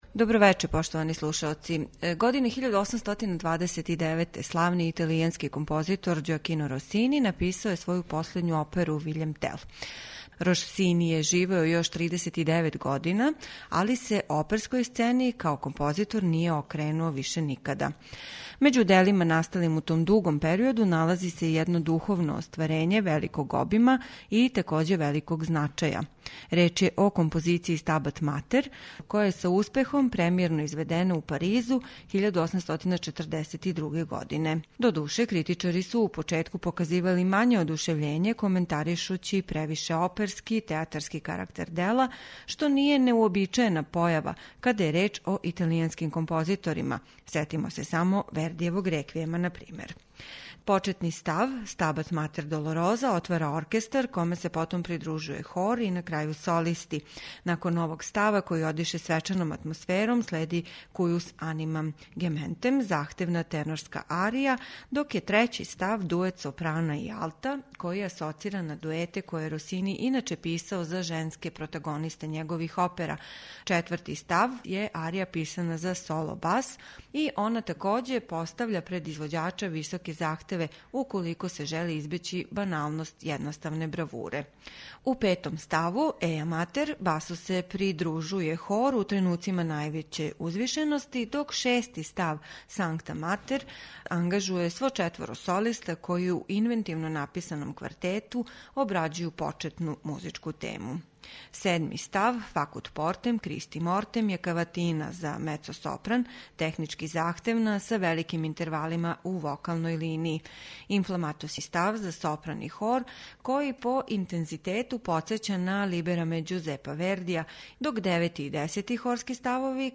Славни италијански оперски мајстор Ђоакино Росини аутор је вокално-инструменталне композиције Стабат матер, коју ћете вечерас слушати.
Снимак који ћемо вечерас емитовати остварили су (уз квартет солиста) Летонски државни хор и Национални симфонијски оркестар.